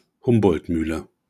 Audioaufzeichnung der Aussprache eines Begriffs. Sprache InfoField Deutsch Transkription InfoField Humboldtmühle Datum 15.